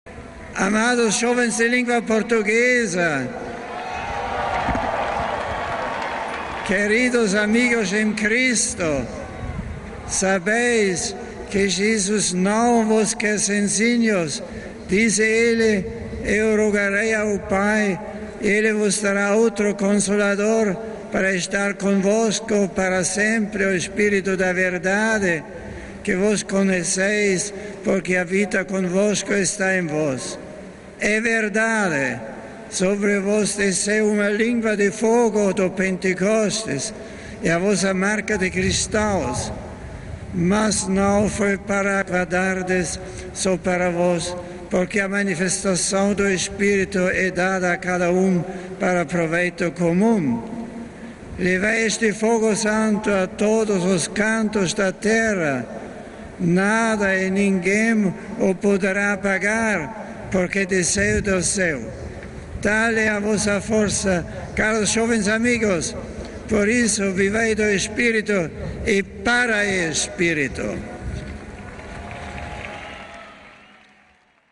Bento XVI dirigiu depois saudações aos jovens nas suas linguas: esta a saudação em português.